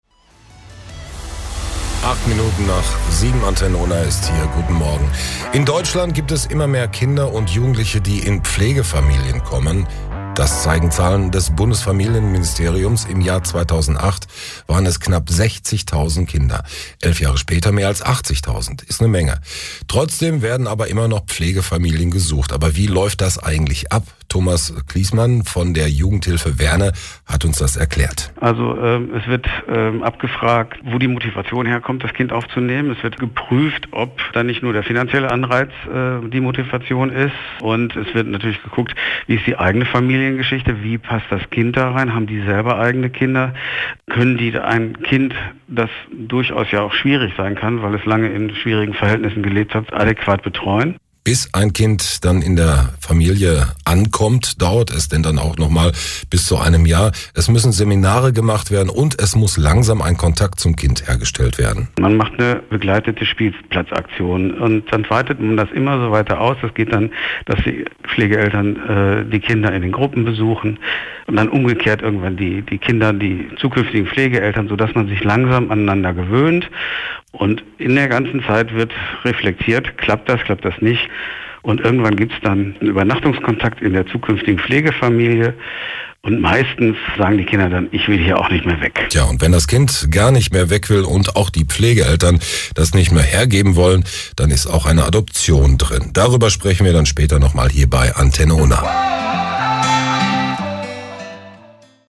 Antenne_Unna_Mai2019_Mitschnitt_Jugendhilfe_Werne_-_.mp3